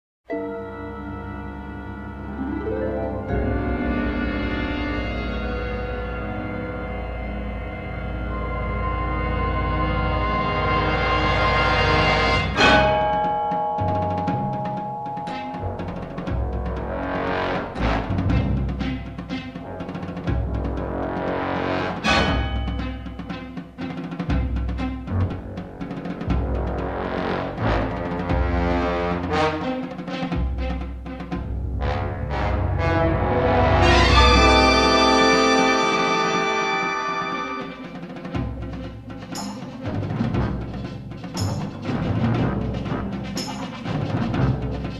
harsh and slightly more contemporary feel